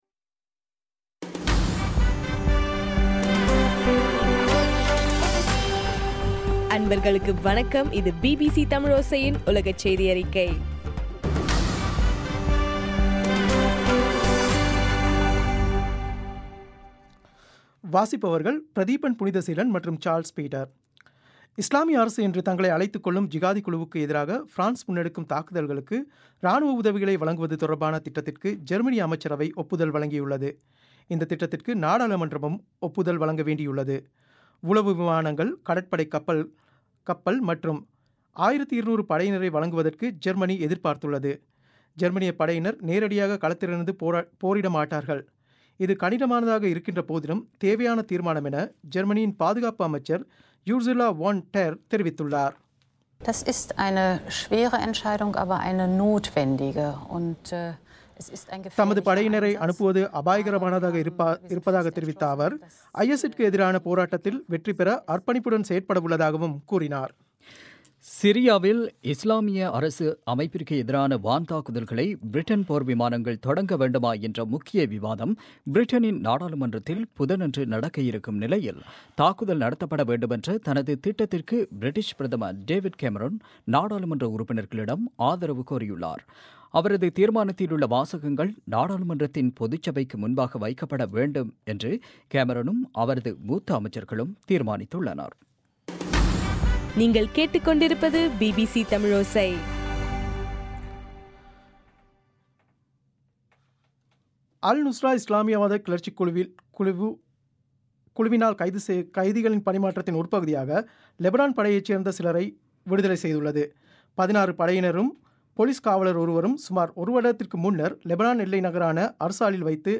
இன்றைய (டிசம்பர் 1) பிபிசி தமிழ் செய்தியறிக்கை